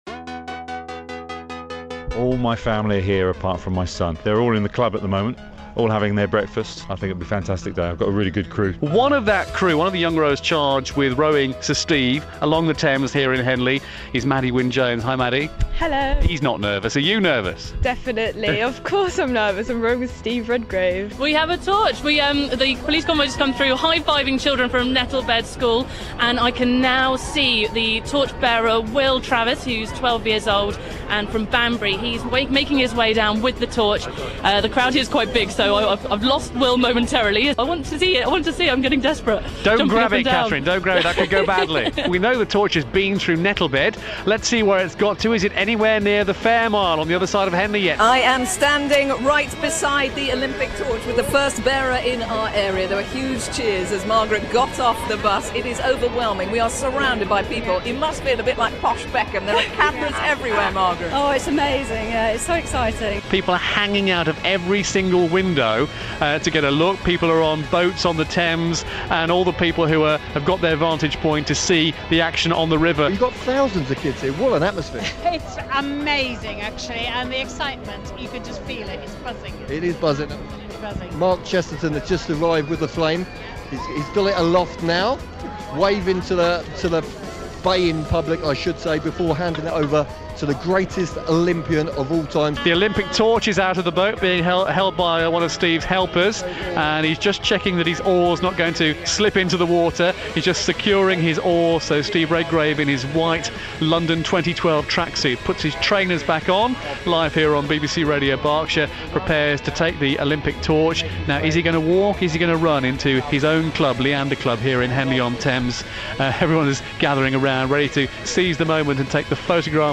The Olympic torch relay came to Berkshire this morning. Here are the early highlights from BBC Radio Berkshire.